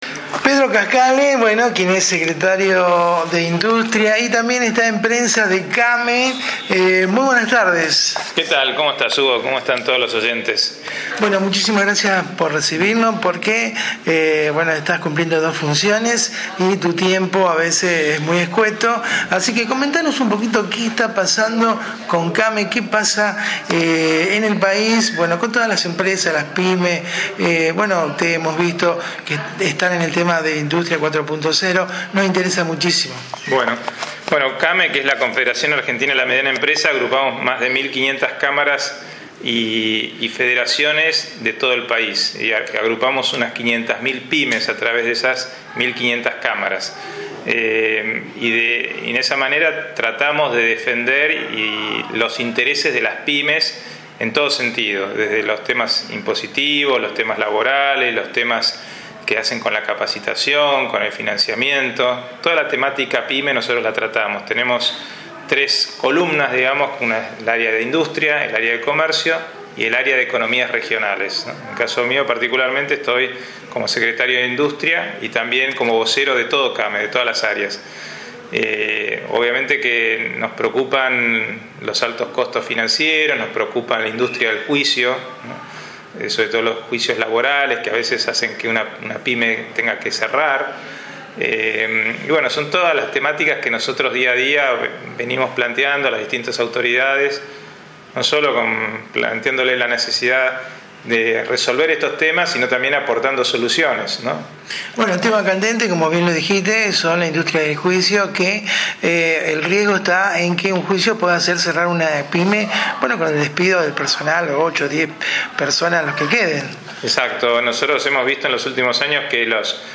La nota la realizamos en el nuevo edifico totalmente reciclado de increíble belleza arquitectónica ubicado en Leandro N. Alem 452 C.A.B.A donde funcionan todas sus oficinas.